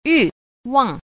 1. 欲望 – yùwàng – dục vọng
yu_wang.mp3